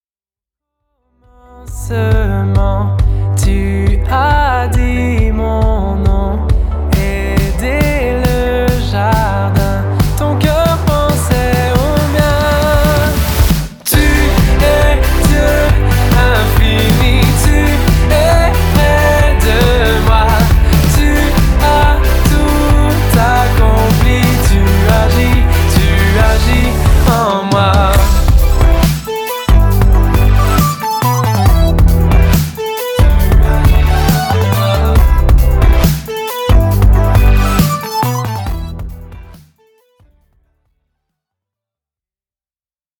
chants de louange aux accents électro